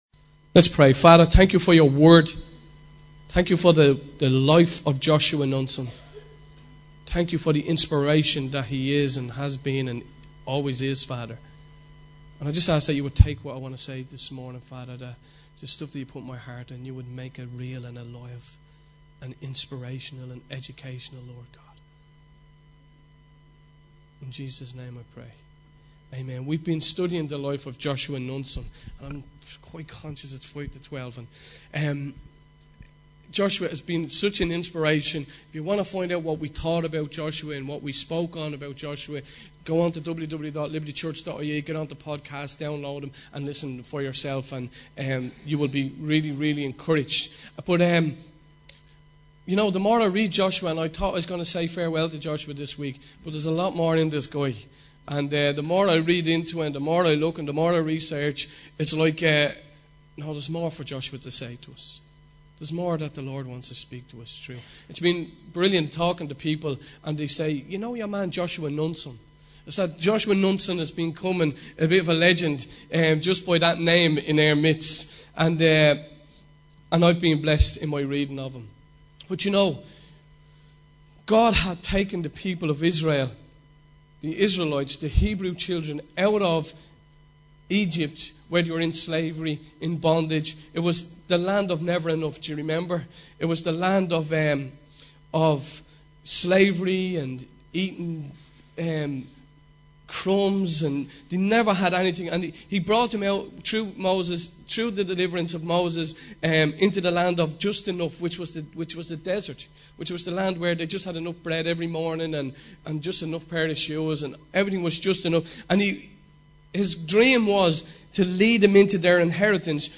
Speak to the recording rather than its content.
Recorded Live at Liberty on 07 March 2010